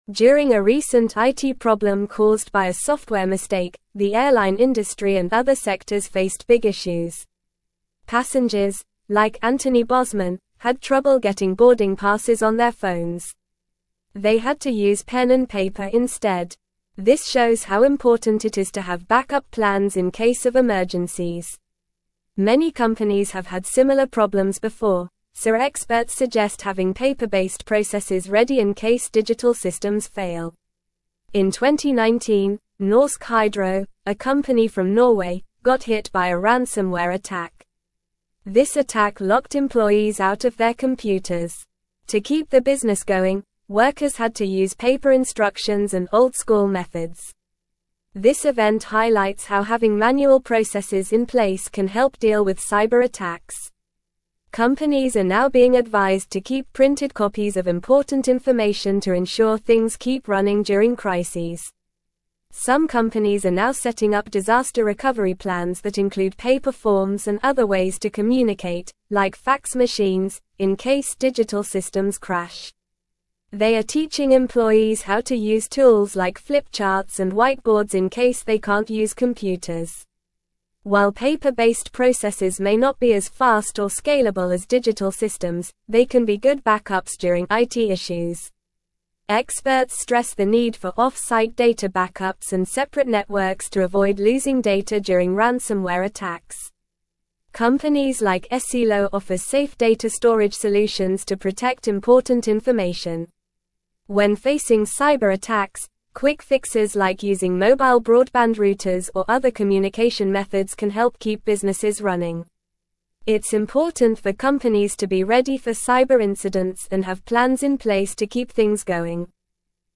Normal
English-Newsroom-Upper-Intermediate-NORMAL-Reading-The-Importance-of-Manual-Processes-During-IT-Failures.mp3